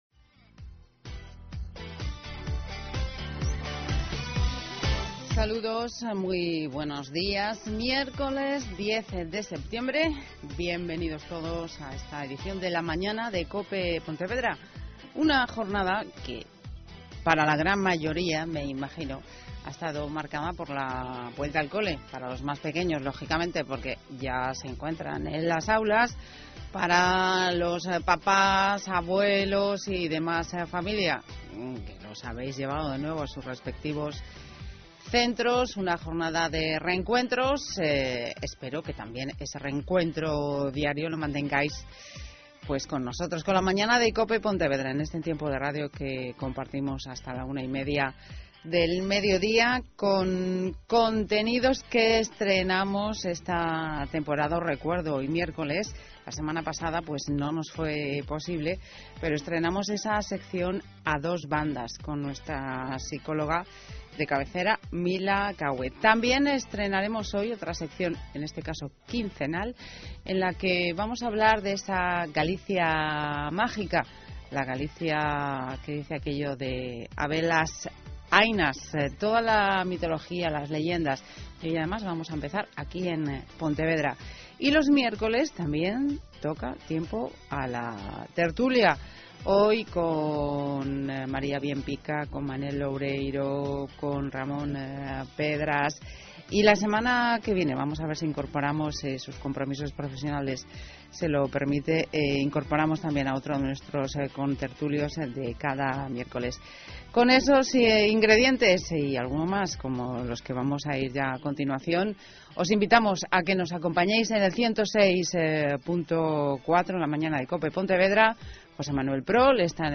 Radio: Podcast «A dos Bandas». COPE Pontevedra. 10 Septiembre 2014
Os dejo a continuación con el podcast de mi intervención en el programa «A Dos Bandas» de COPE Pontevedra.